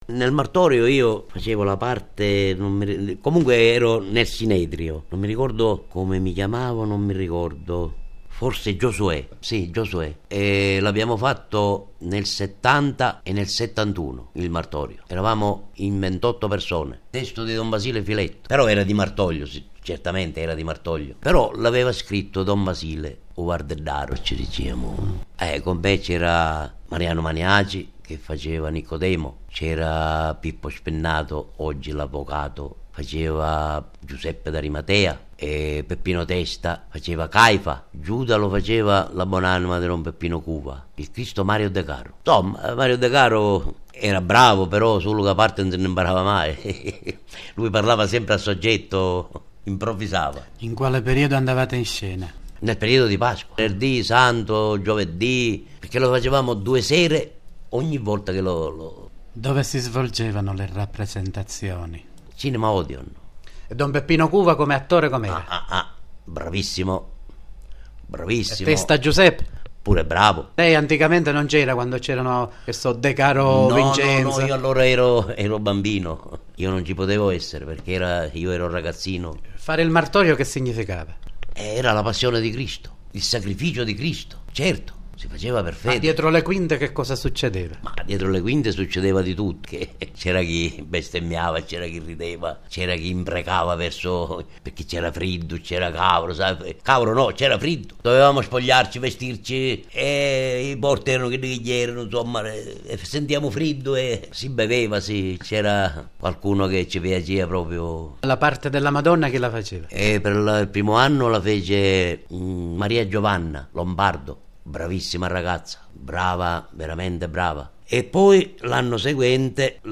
I documenti presenti in questo archivio sono in formato mp3 e sono stati digitalizzati e restaurati recentemente da supporti audio tradizionali. La qualità di alcuni, comunque, non è ottimale.